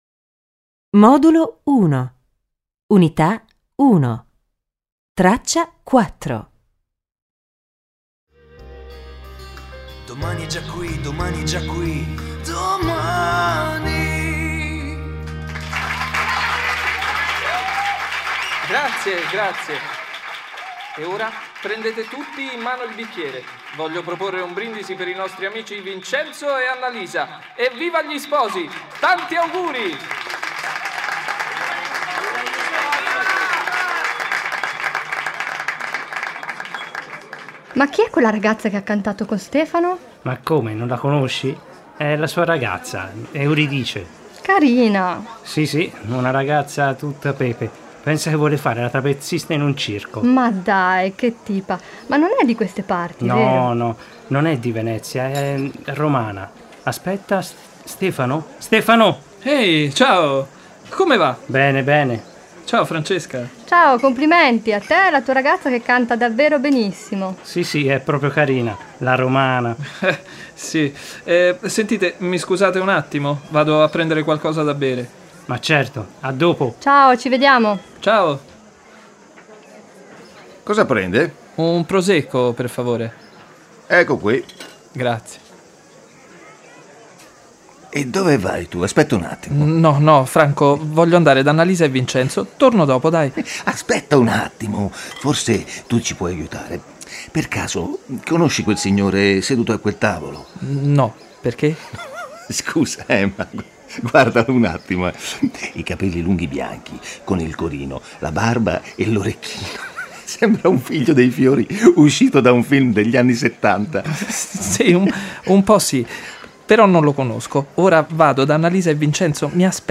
Ascolta il dialogo a segna una X nel tuo foglio ogni volta che senti una nuova persona che «chiacchiera».